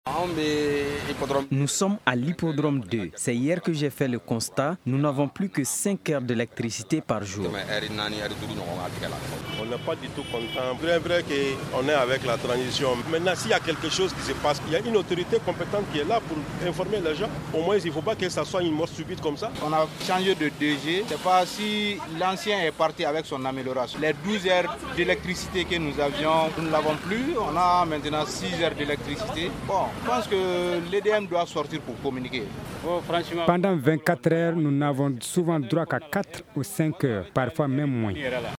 Une-MICROTROTOIR-FR-DELESTAGE.mp3